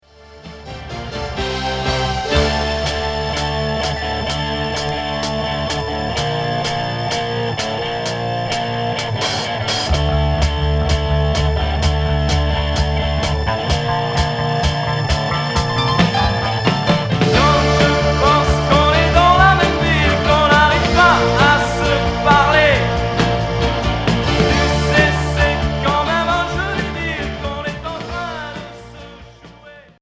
Rock progressif